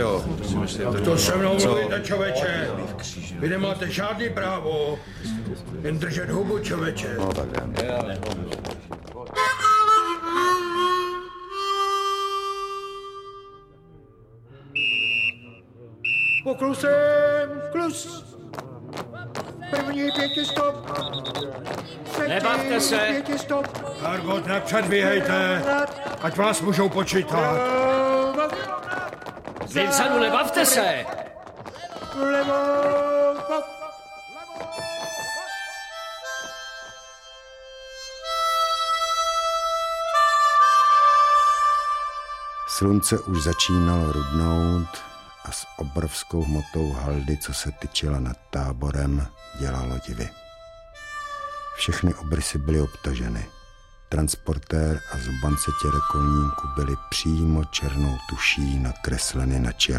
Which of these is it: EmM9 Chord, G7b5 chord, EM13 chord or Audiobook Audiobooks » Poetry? Audiobook Audiobooks » Poetry